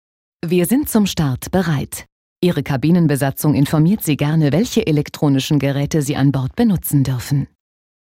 She is known for her versatile, warm and sovereign voice.
Sprechprobe: Sonstiges (Muttersprache):
german female voice over talent. Her extensive media experience includes leading positions in television as well as direction of radio commercials and event presentation